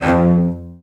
CELLOS.GN2-L.wav